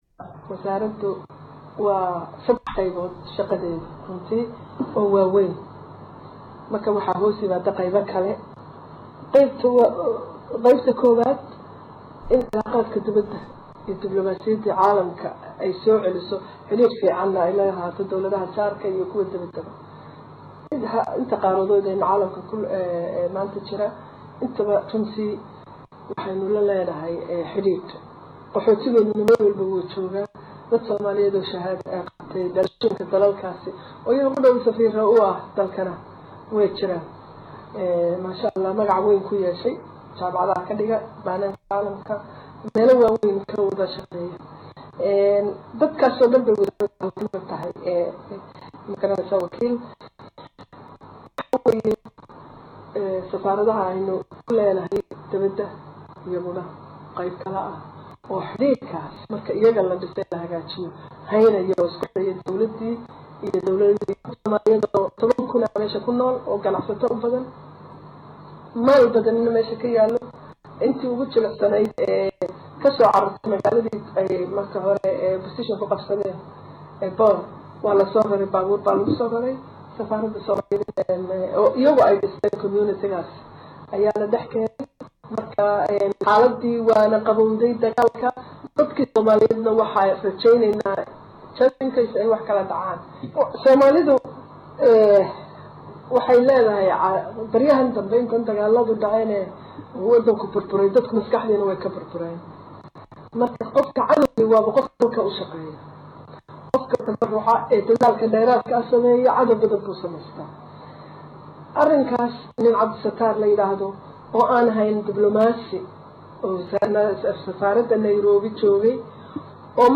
Fowsiyo Yuusuf Xaaji Aadan oo wareysi gaar ah siisay Warbaahinta Dowlada ayeey kaga hadashay waxqabadka wasaaradda ee sanadki hore, iyadoo intaa ku dartay in wasaaraddu ay guulo waaweeyn ka gaartay xiriirta caalamka.